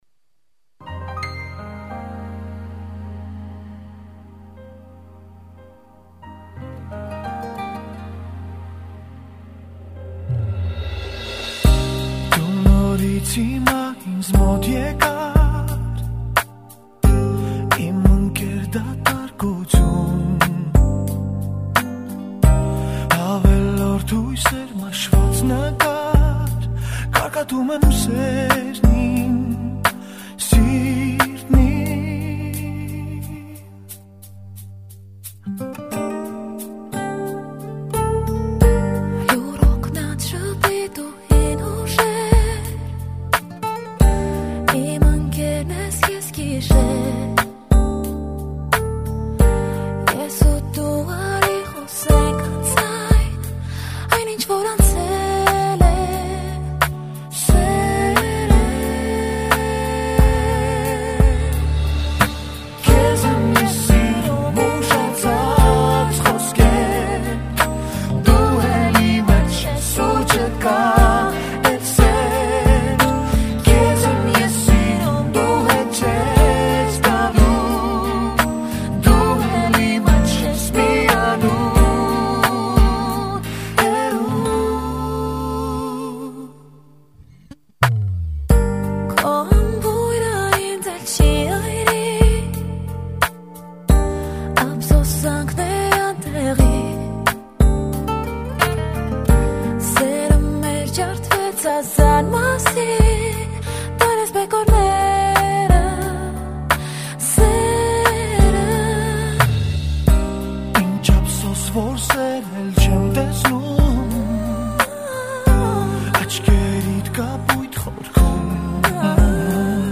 准确的说这首亚美尼亚很深情的对唱,但是很冷门，我觉得很好听，相信这首歌肯定会红的。
寂静的夜晚，很适合悲凉的感觉蔓延在漆黑的夜空，直至黎明的到来。